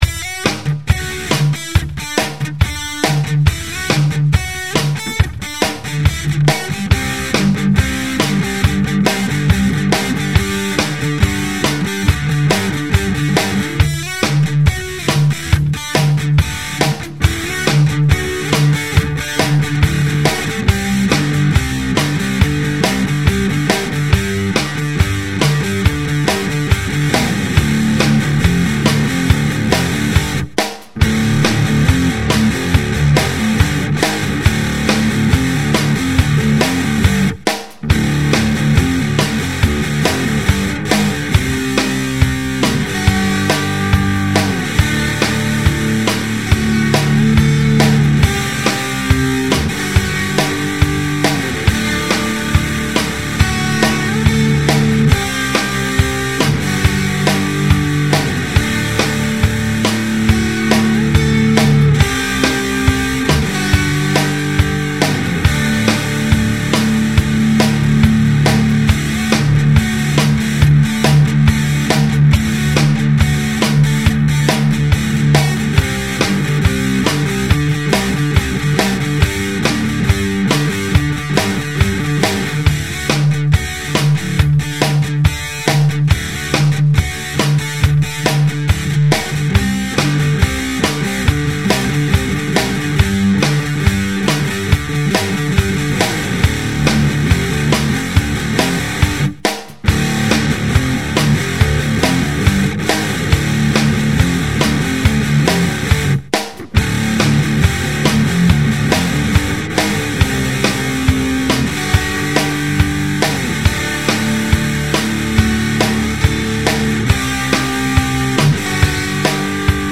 • Another early recording. Still using stock drum loops too. Multi-track with 1 drum track and 3 electric guitars.